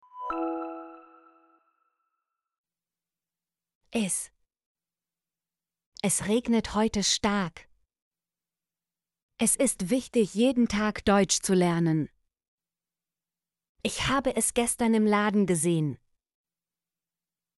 es - Example Sentences & Pronunciation, German Frequency List